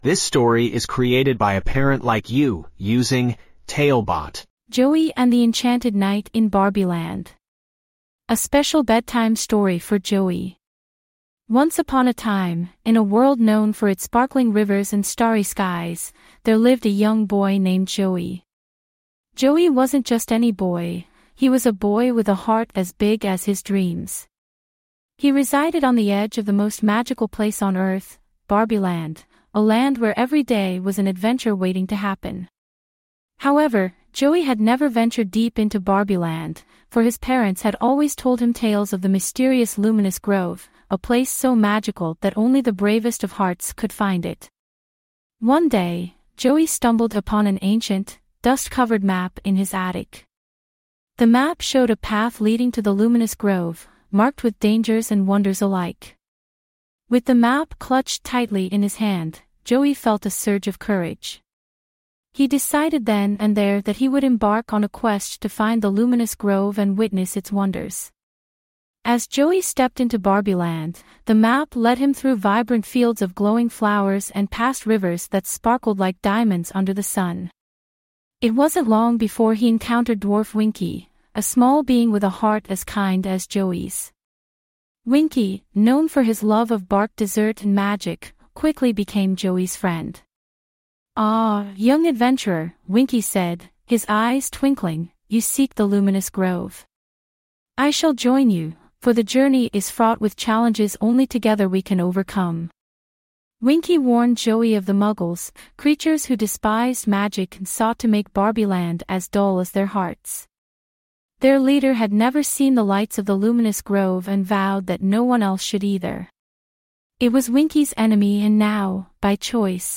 5 minute bedtime stories.
TaleBot AI Storyteller